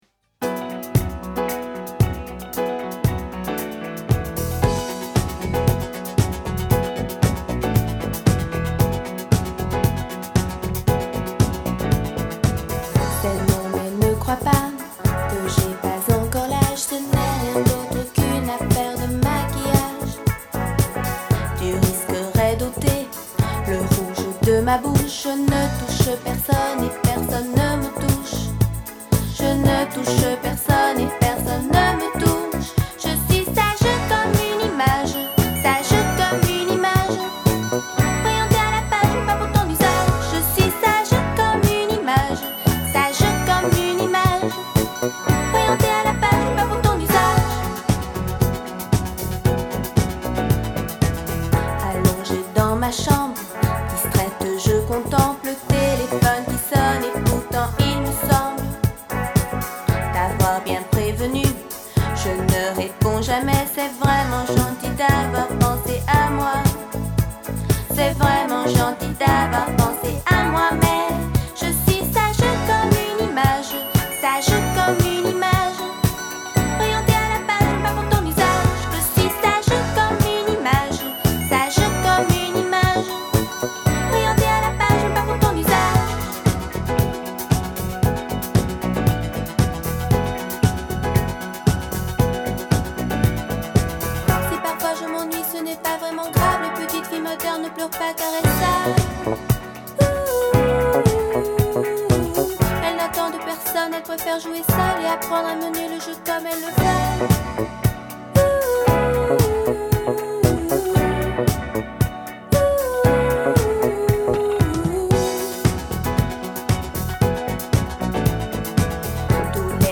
Filed under disco